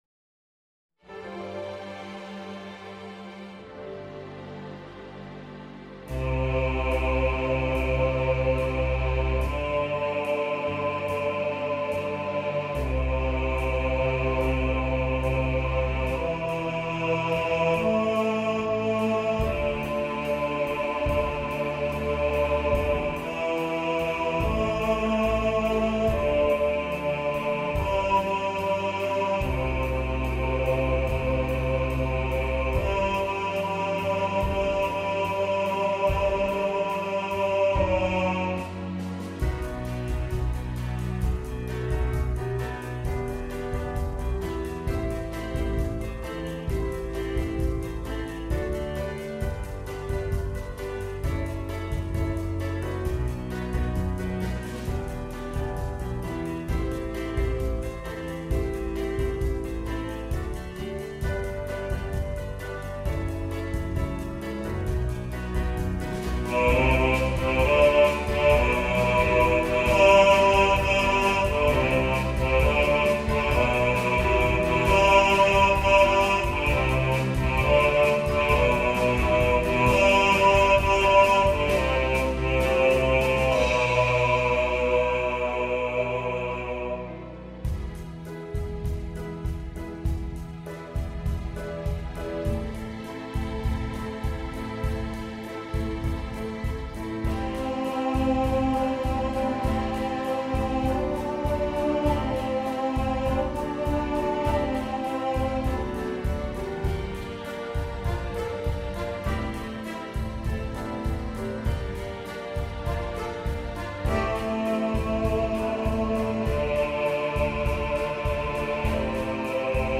Bacharach Medley – Bass | Ipswich Hospital Community Choir